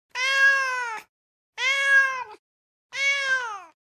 Download Free Cat Sound Effects | Gfx Sounds
This impressive and originally recorded SFX set of Cat Sounds provides all the core Cat Vocalizations you’ll need!
Domestic-cat-meowing-wanting-attention-3.mp3